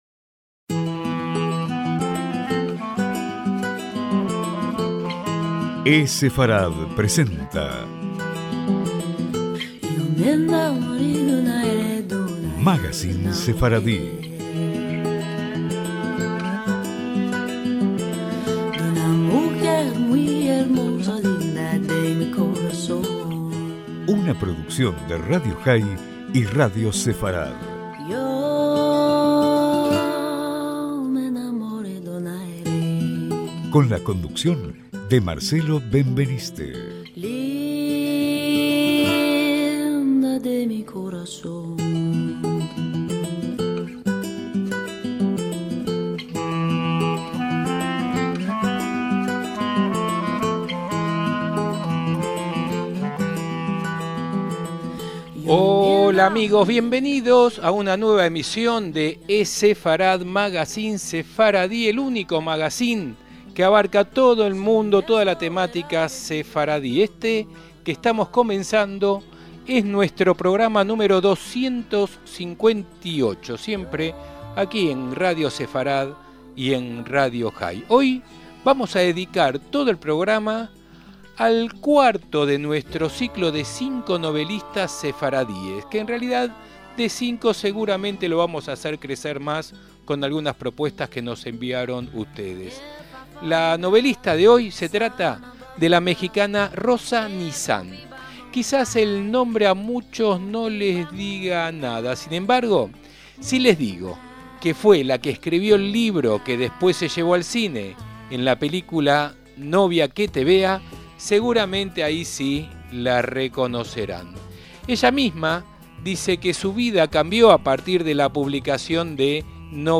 El aporte musical es de la propia película, con el abuelo cantándole a su nieta Adio kerida mientras maneja su auto a través de las calles de México.¡Semanada buena!